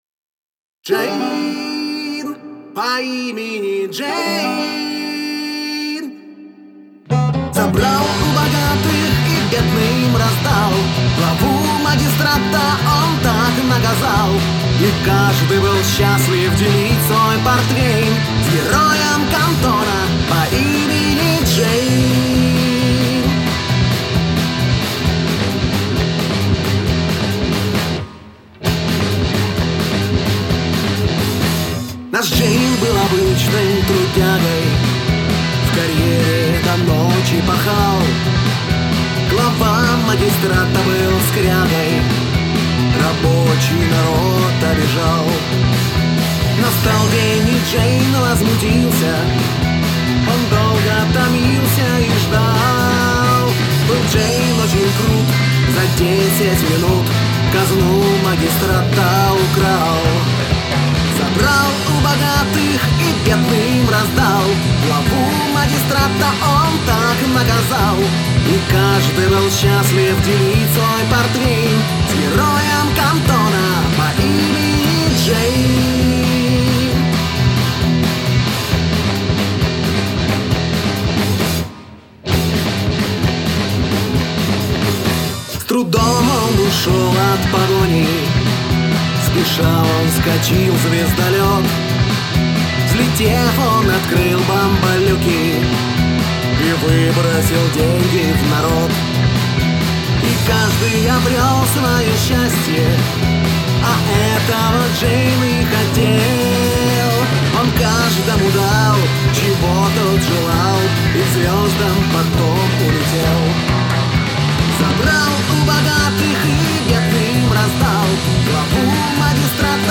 Russian rendition